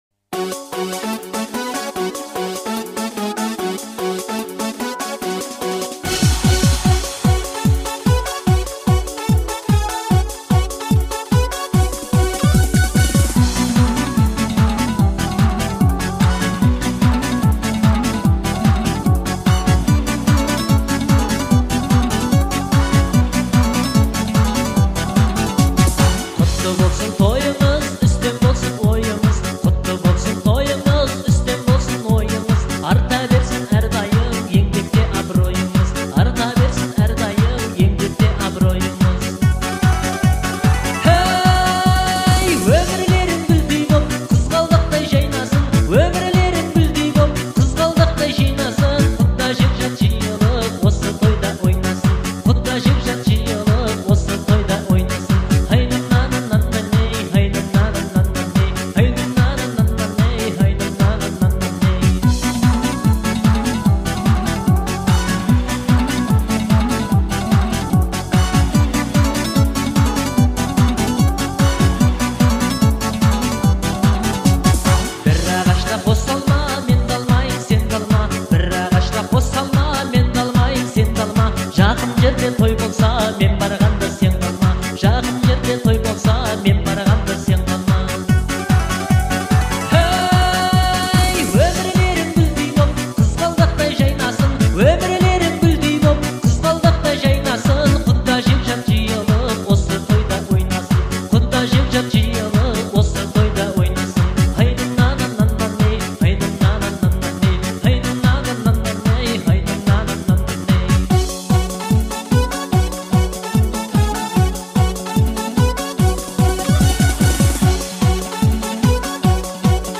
это яркая и радостная песня